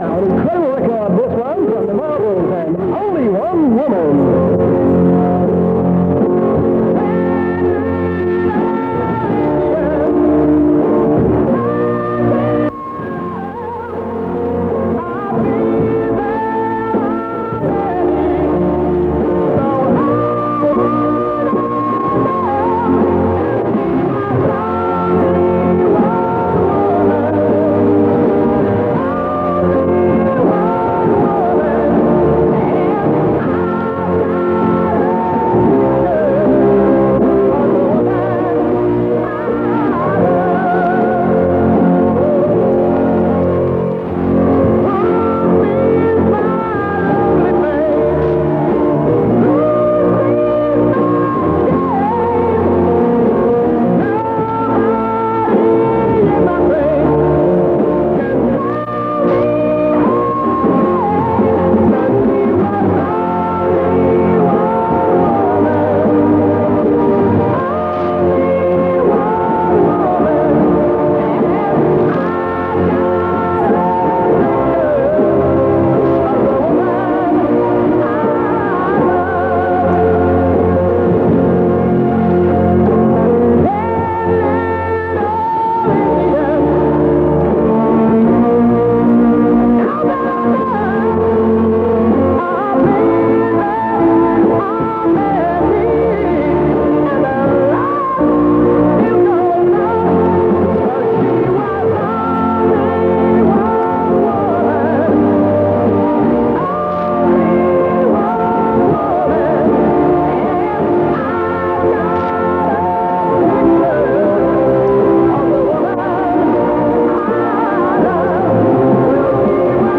A short off-air recording from the same broadcast as above, Sunday 25th March 1979. Recorded in Leeds from 7340kHz.